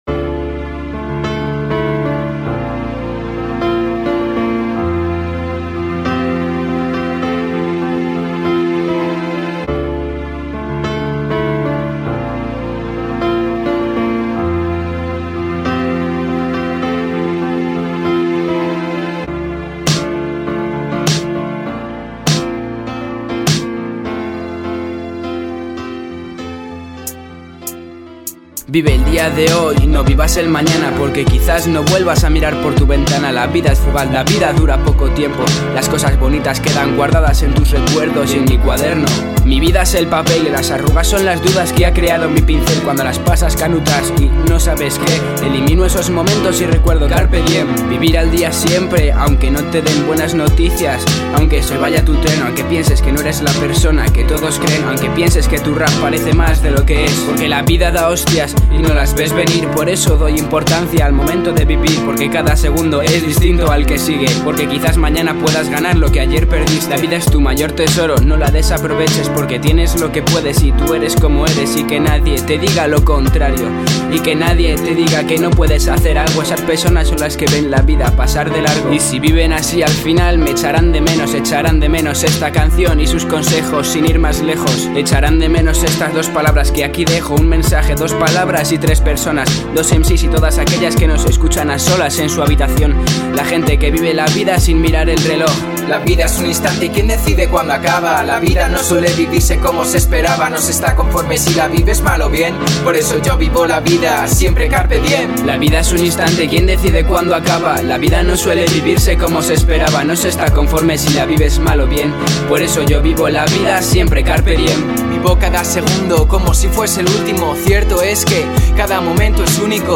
MC al que le debía una colabo desde hace mucho
fuimos al estudio